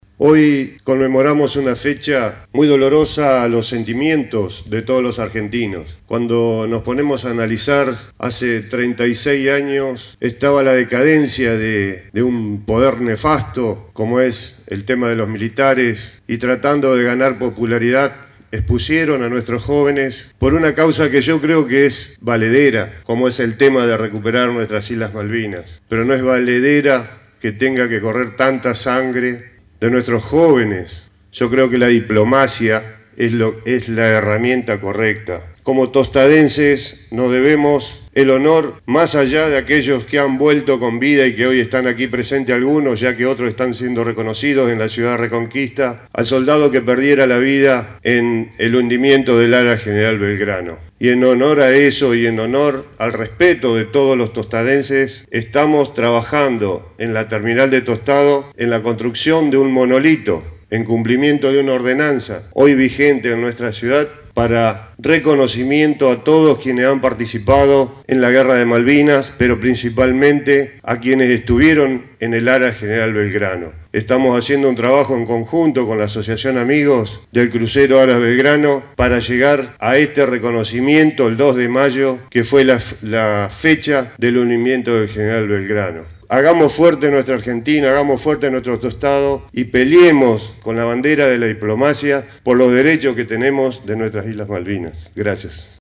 Un sencillo y emotivo acto en conmemoración por el Día del Veterano y de los Caídos en la Guerra de Malvinas se llevó a cabo en el Monolito ubicado en la intersección de las calles Saavedra y 2 de Abril de Tostado.
La palabra del intendente de Tostado Enrique Mualem:
Enrique-Mualem-acto-Malvinas-en-Tostado.mp3